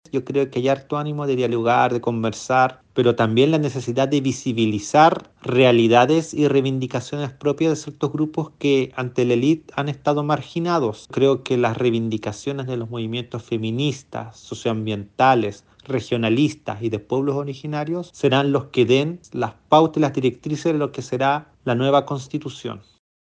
Luis Jiménez, escaño reservado del pueblo aymara, dijo que espera disposición al diálogo durante esta primera jornada, aunque recalcó que se plantearán distintas demandas.